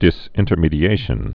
(dĭs-ĭntər-mēdē-āshən)